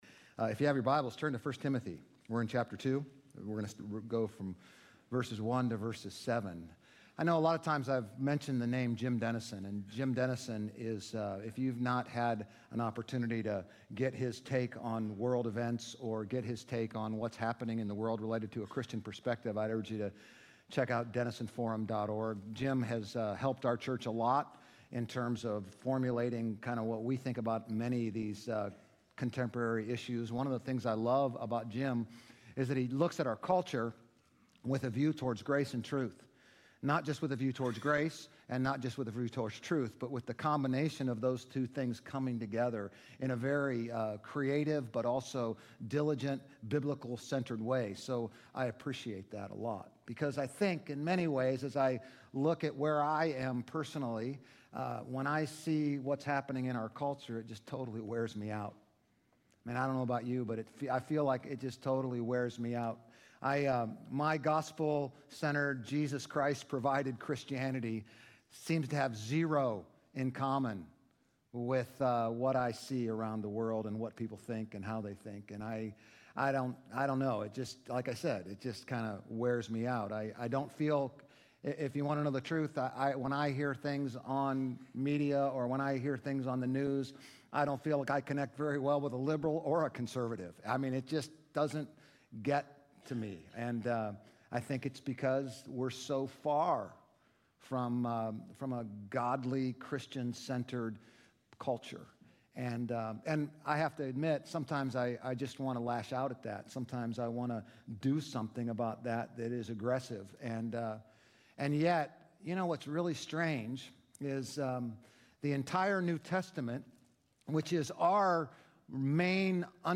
GCC-OJ-June-12-Sermon.mp3